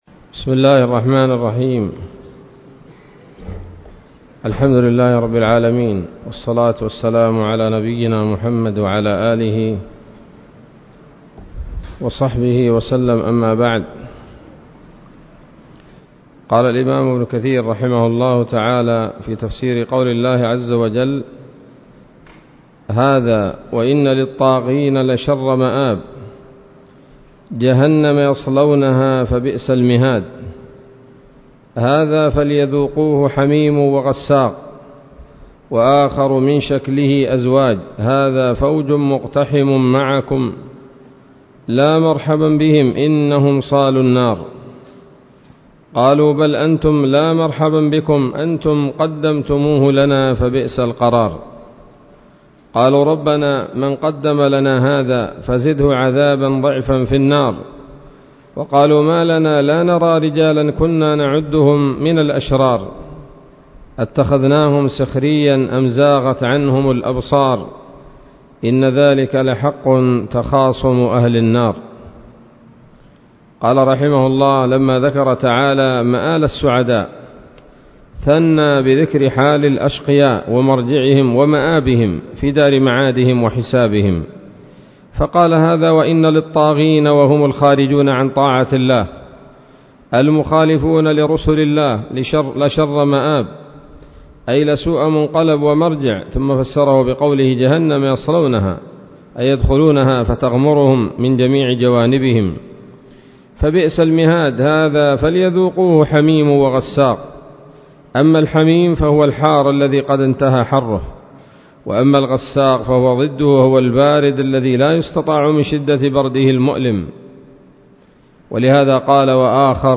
الدرس الحادي عشر من سورة ص من تفسير ابن كثير رحمه الله تعالى